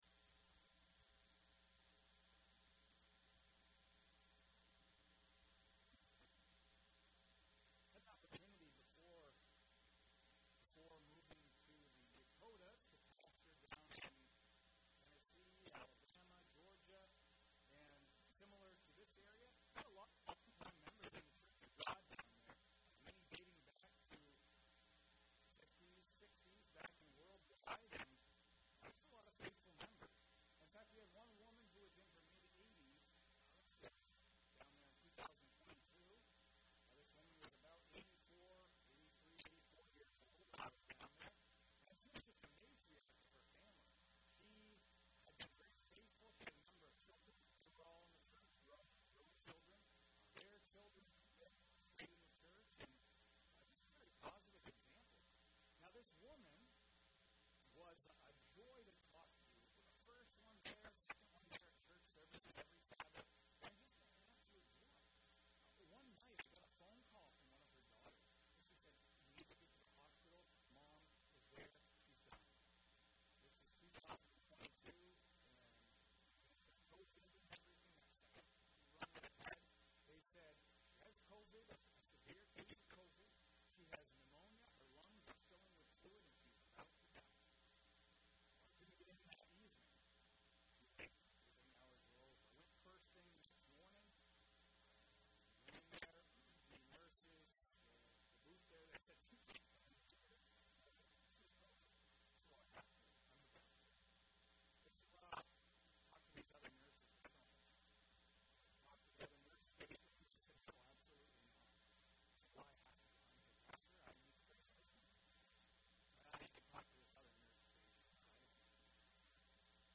This sermon discusses why God seems to answer immediately for some, but not for others and gives 8 reasons why God may not heal immediately.